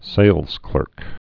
(sālzklûrk)